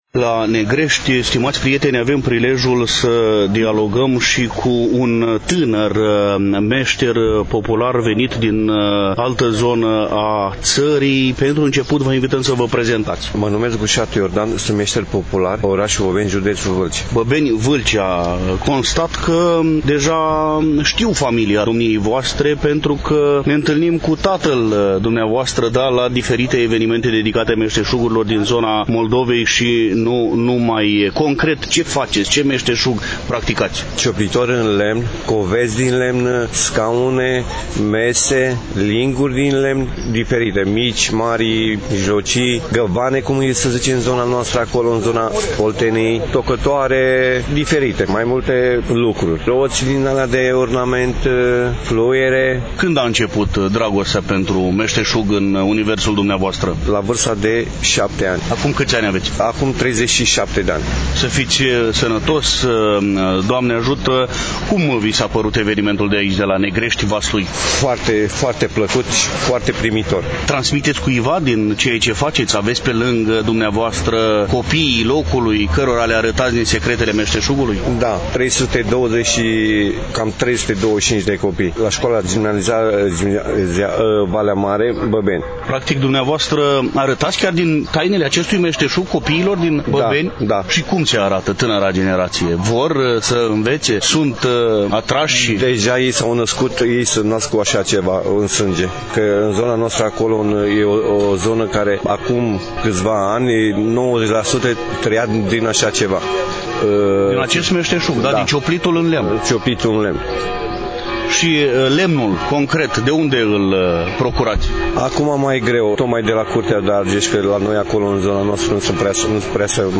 Relatăm, după cum bine știți, de la prima ediție e Târgului lui Pintilie călătorul, eveniment care s-a desfășurat, la Negrești, județul Valsui, în zilele de 29 și 30 iulie 2023.